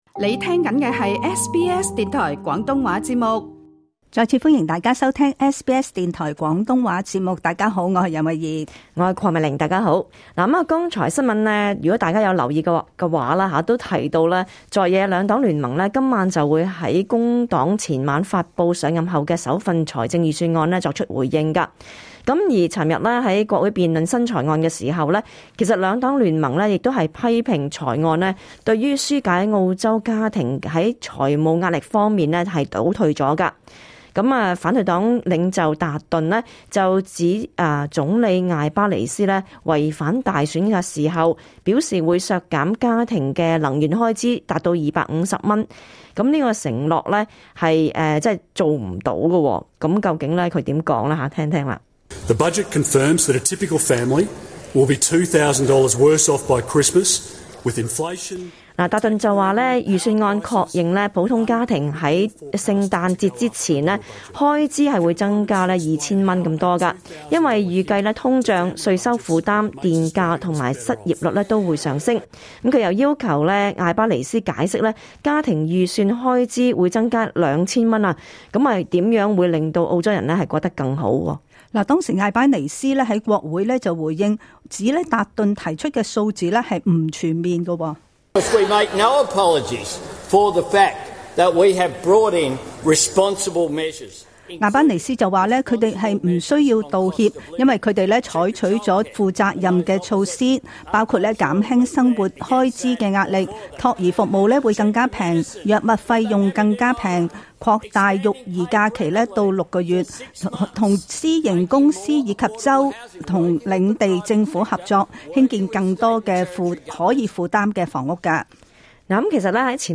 時事報道